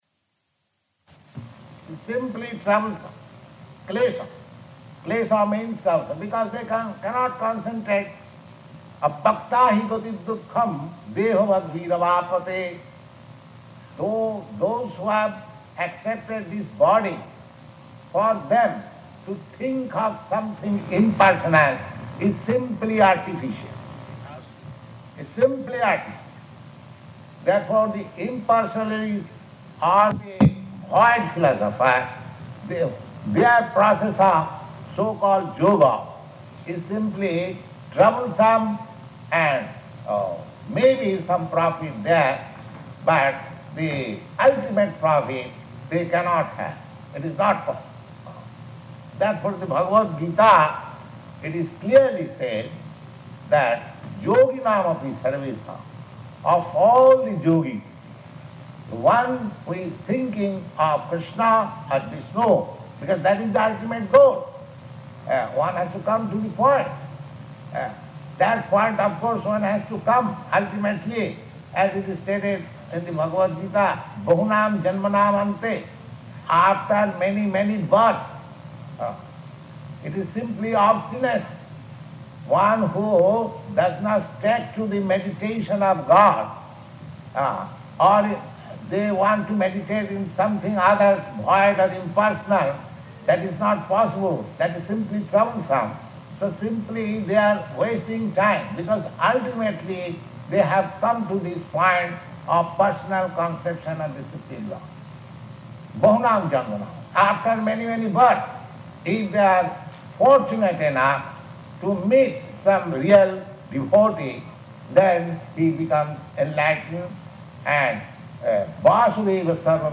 Bhagavad-gītā Lecture [partially recorded]
Bhagavad-gītā Lecture [partially recorded] --:-- --:-- Type: Bhagavad-gita Dated: July 20th 1968 Location: Montreal Audio file: 680720BG-MONTREAL.mp3 Prabhupāda: It is simply troublesome, kleśa.